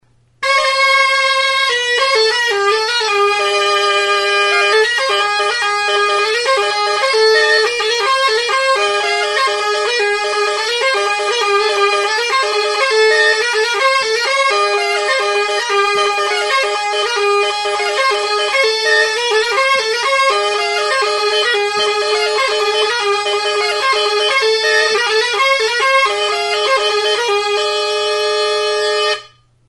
Instrumentos de músicaAlboka
Aerófonos -> Lengüetas -> Simple (clarinete)
Grabado con este instrumento.
ALBOKA
Klarinete bikoitza.